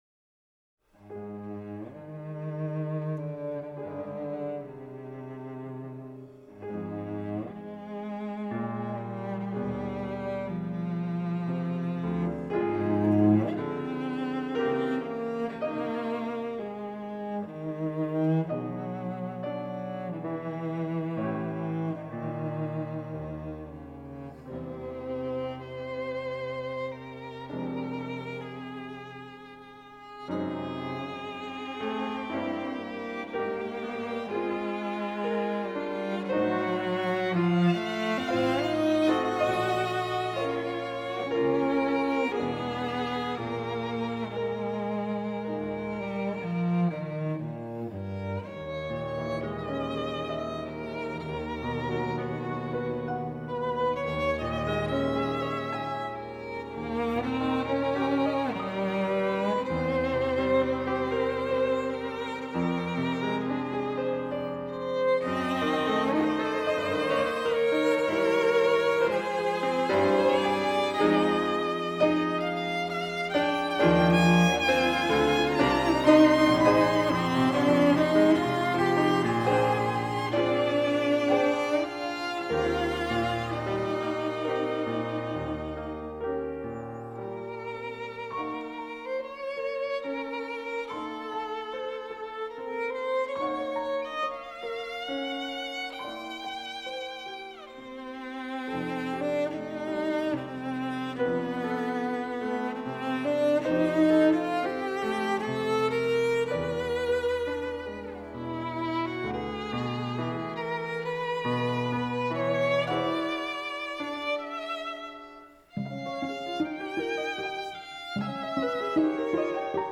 Trio à clavier
violoncelle